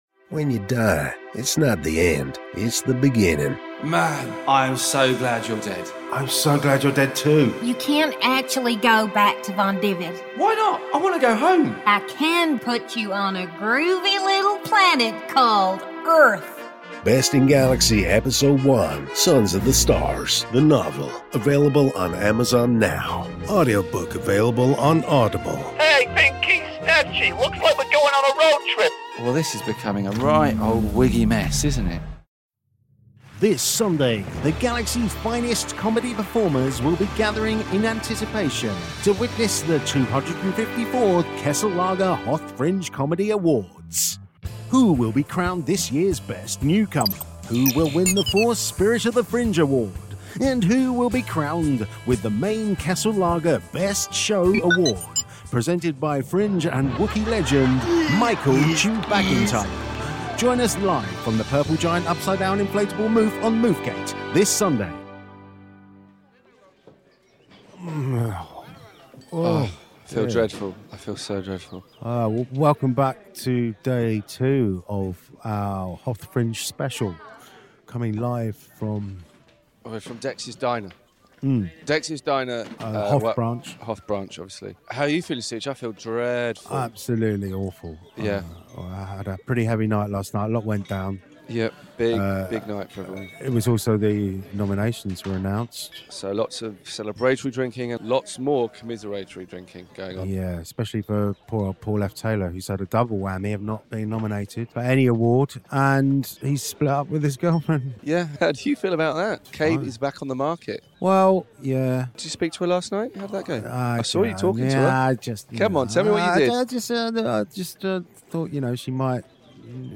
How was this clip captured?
This is a free parody podcast, not affiliated to Star Wars or Disney and done just for fun cos we just love Star Wars!